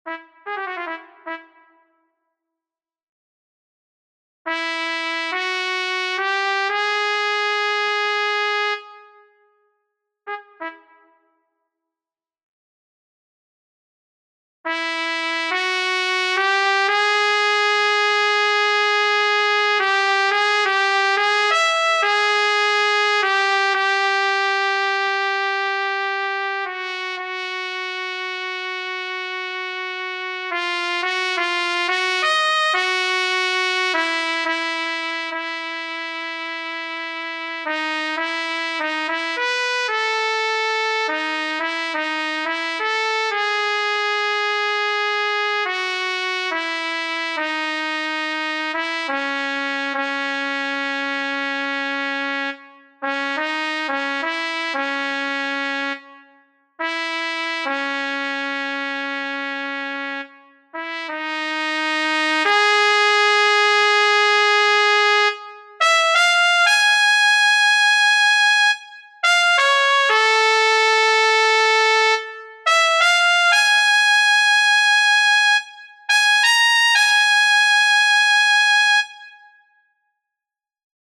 trąbka